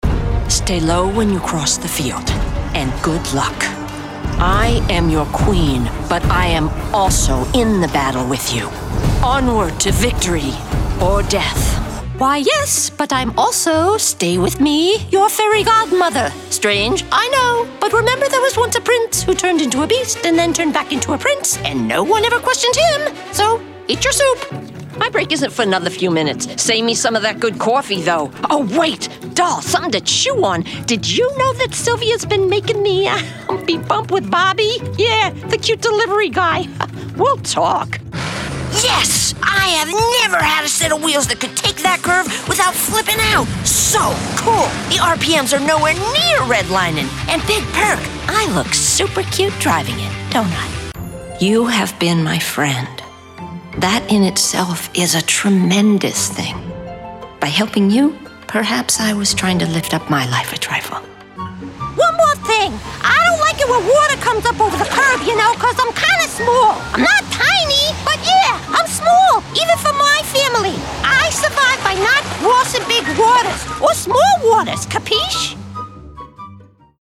In the area of animation and video games, I have the ability for a wide range of characters and bring a strong sense of humor, emotion, playfulness and creativity.
Animation